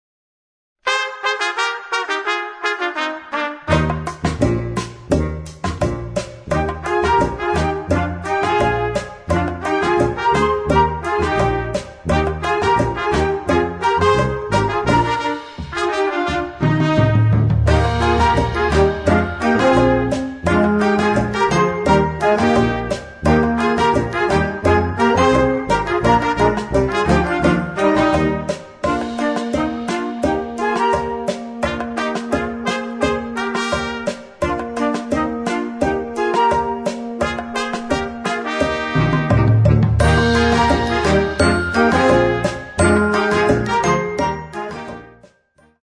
Noten für flexibles Ensemble, 4-stimmig + Percussion.